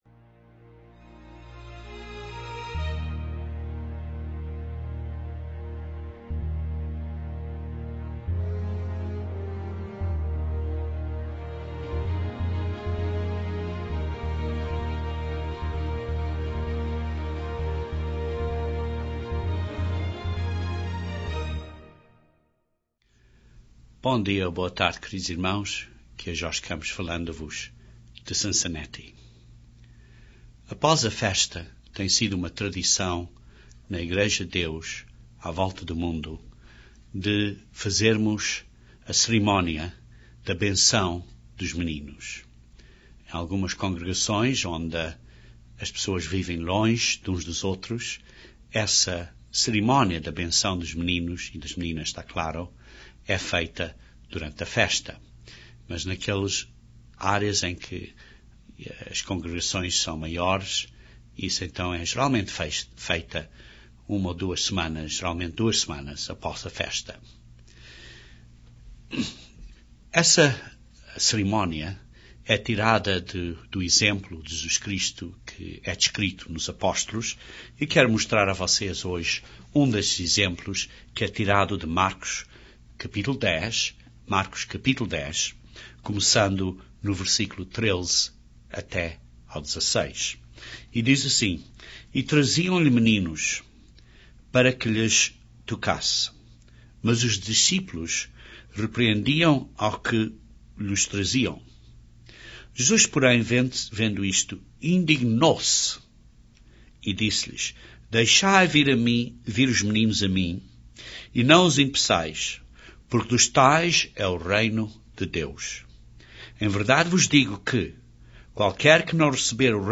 Na oração da cerimónia da benção das criancinhas fazemos quatro pedidos para os nossos filhinhos. Este sermão analiza esses quatro pedidos, aplicando-os a nós, como os verdadeiros 'filhinhos de Deus' a caminho de virmos a ser 'realmente filhos de Deus'.